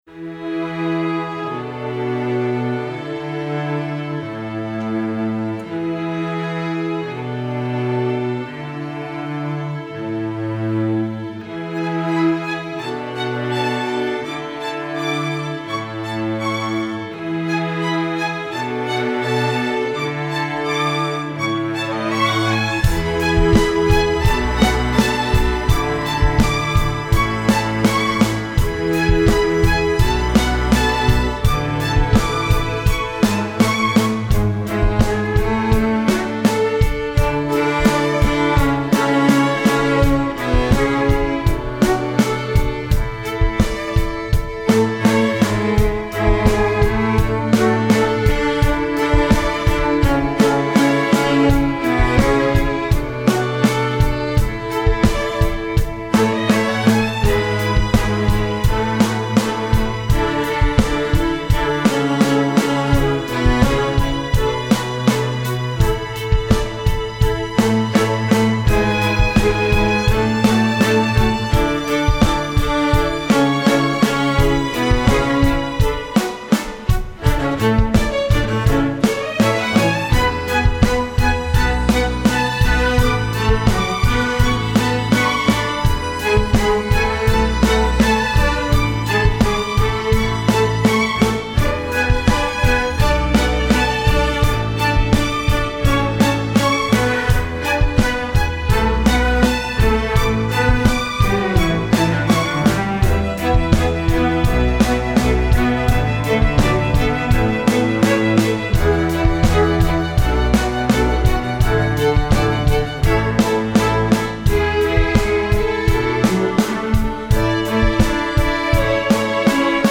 Instrumentation: string orchestra
rock